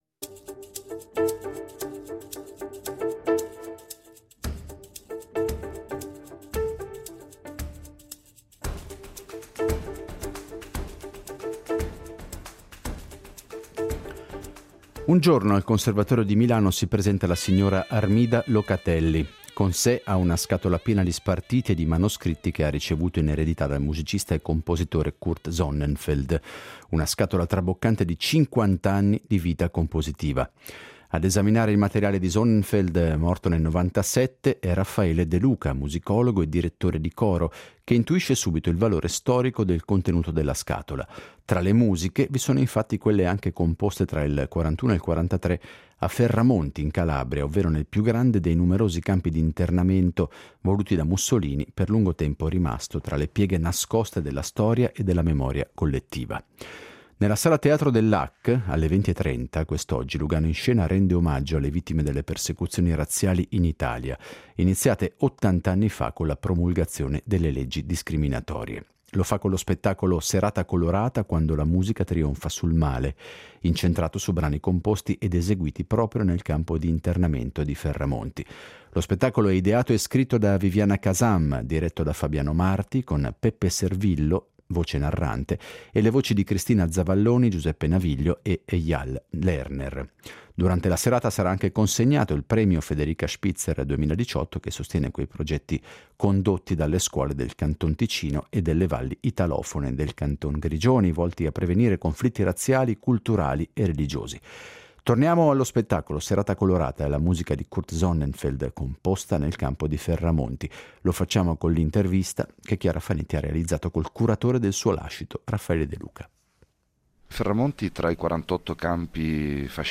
Beppe Servillo, voce narrante